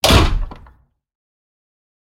doorslam.ogg